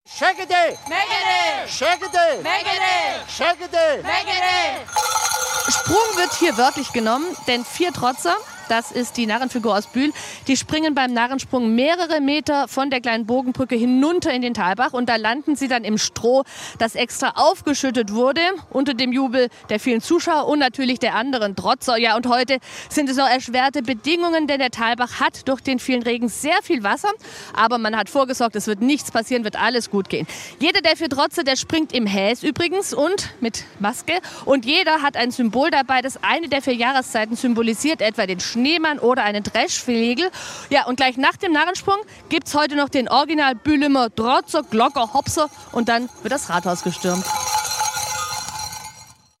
Bühler Narrensprung
Beim Narrensprung hüpfen die vier Drotzer mehrere Meter von der kleinen Bogenbrücke hinunter in den Talbach.